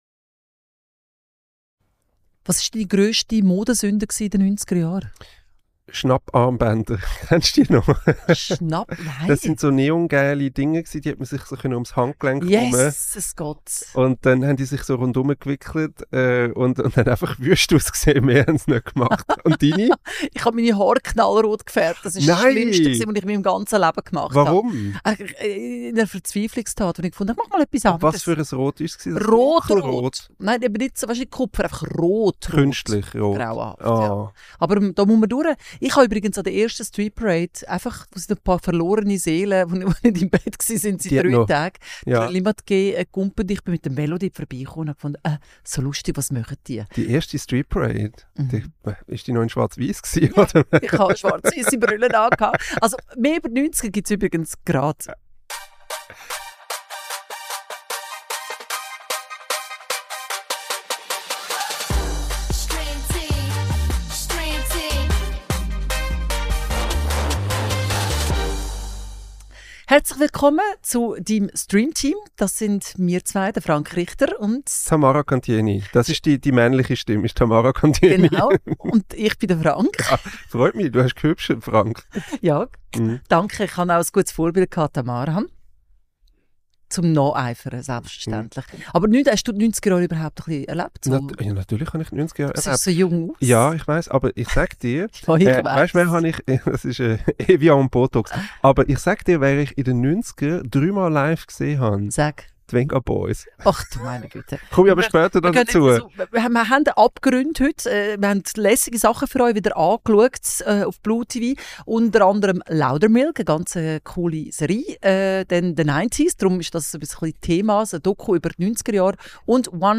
Episode 8 | Loudermilk, The 90s, One Battle After Another ~ Streamteam | Der erste Film- und Serienpodcast auf Schweizerdeutsch Podcast